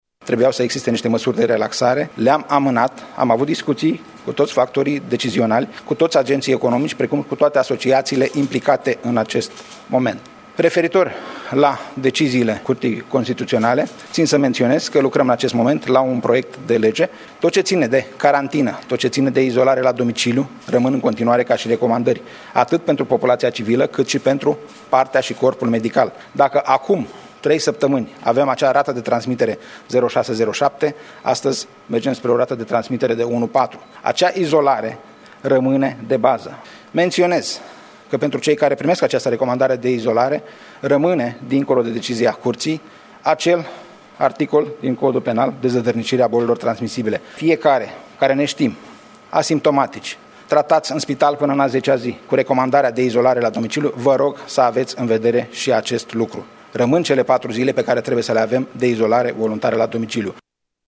Ministrul Sănătății, Nelu Tătaru, și secretarul de stat, Bogdan Despescu, au susținut declarații de presă în urmă cu puțin timp, după întâlnirea premierului Ludovic Orban cu miniștri și șefi de instituții cu atribuții de control în contextul crizei COVID-19.